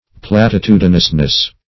-- Plat`i*tu"di*nous*ness , n. [1913 Webster]
platitudinousness.mp3